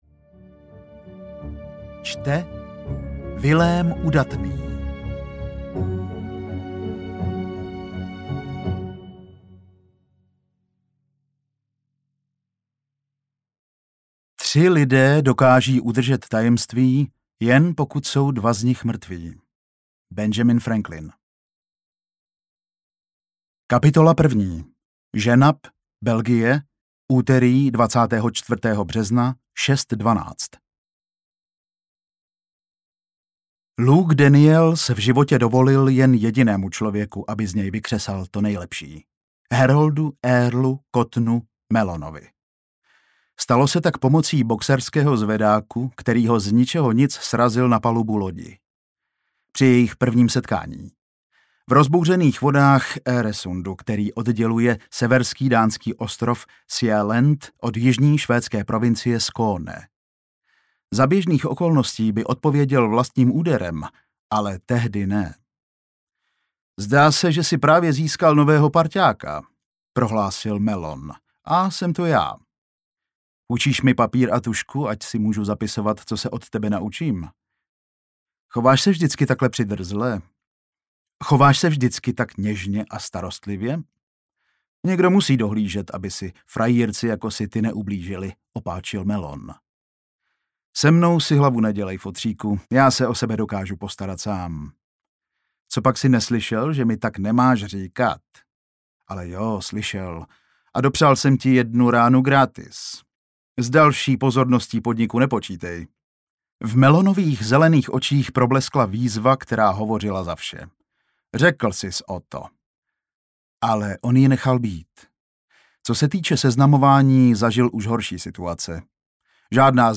Devátý muž audiokniha
Ukázka z knihy
devaty-muz-audiokniha